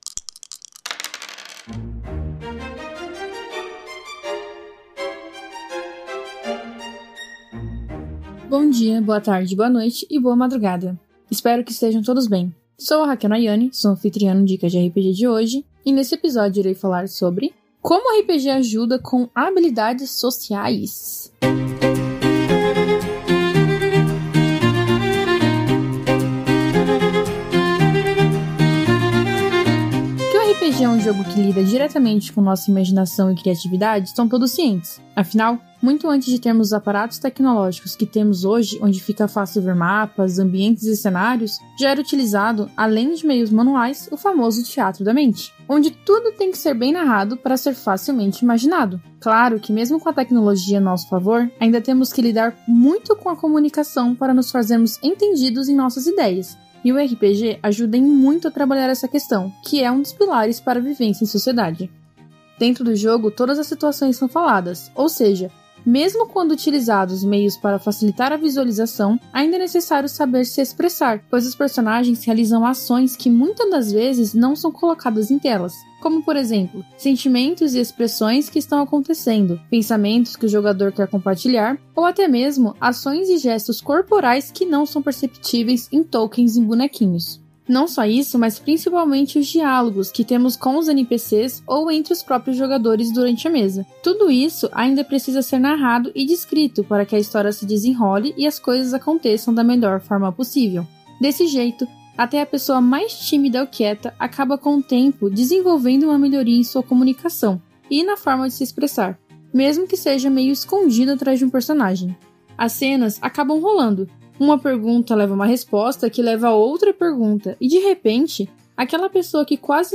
Músicas: Music by from Pixabay